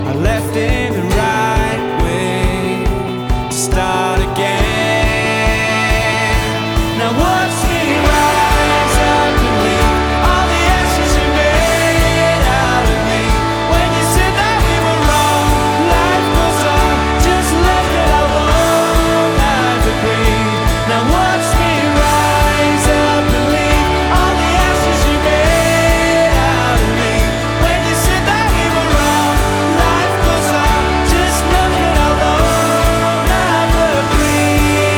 Жанр: Поп музыка / Рок / Альтернатива